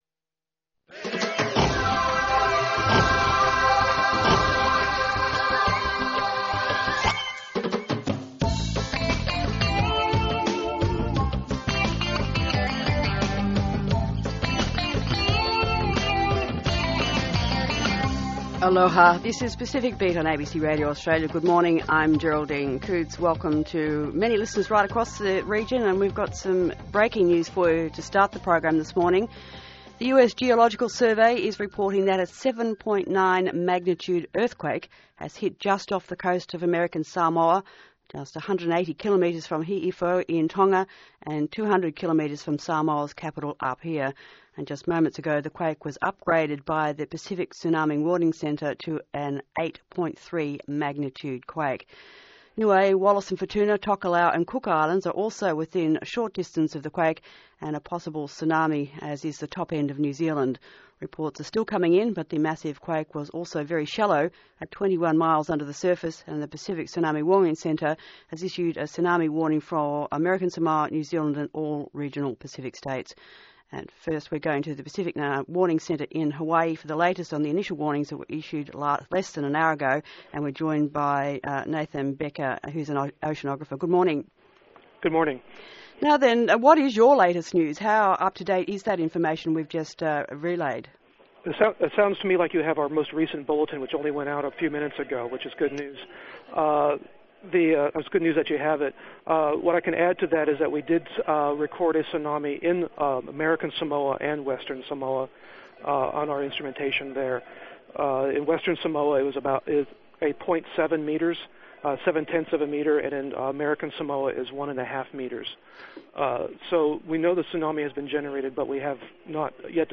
0500 TO 0530–Radio Australia’s Pacific Beat AM program of the Pacific quake and tsunami of September 30, 2009. This program went to air 68 minutes after the first wire flash of a quake off American Samoa, Samoa and Tonga.